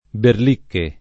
berl&kke] (meno com. berlic [berl&k]) s. m. — nome scherzoso del diavolo, spec. in certe locuz. fig.: far berlicche berlocche, cambiar le carte in tavola; rimanere come berlicche, scornato — come vero n. pr. m., con B- maiusc. nella traduz. it. (1947) delle Lettere di Berlicche di C. S. Lewis